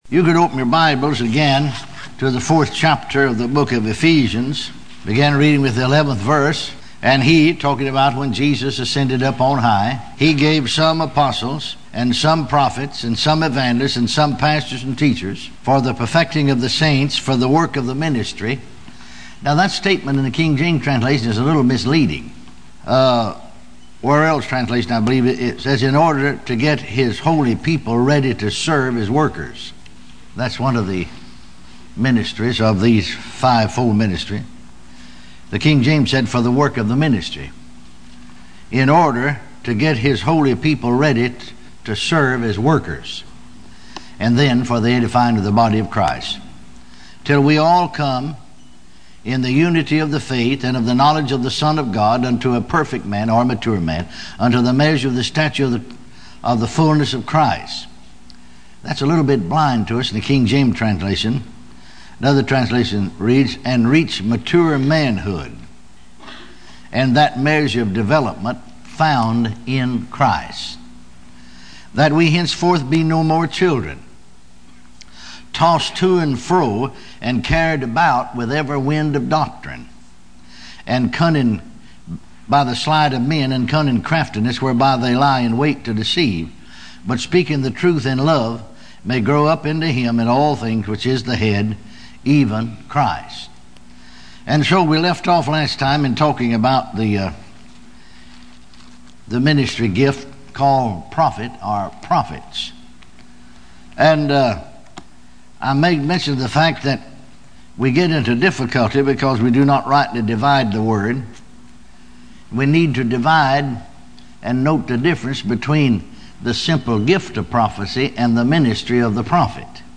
Sermons
Ministry_Conference/Apostles_Prophets